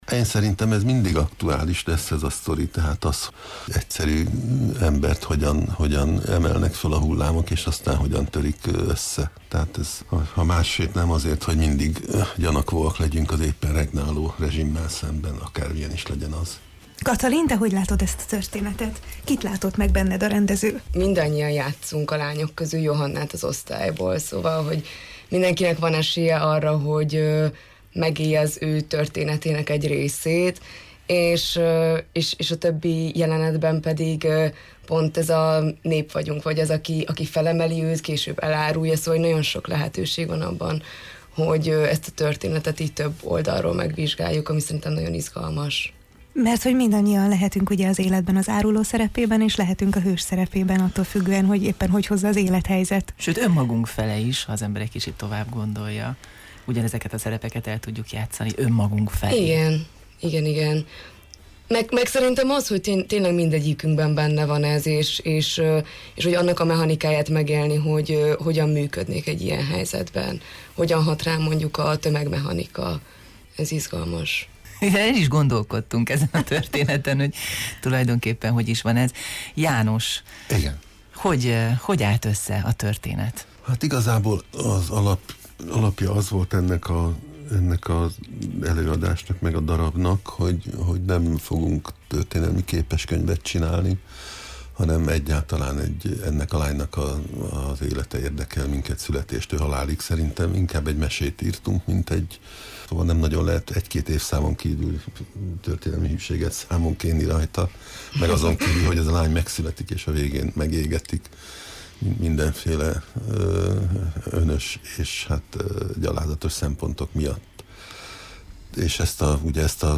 illetve Mohácsi János rendező voltak a vendégeink: